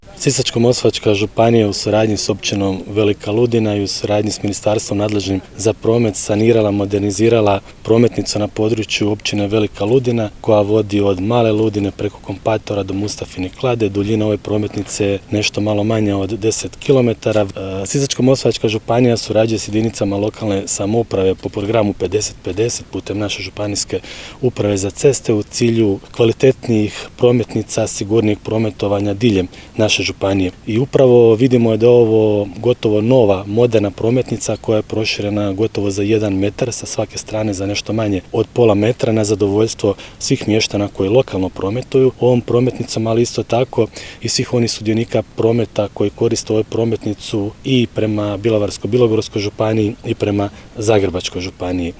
FOTO | Svečano otvorena cesta Mala Ludina-Kompator-Mustafina Klada
Župan Ivan Celjak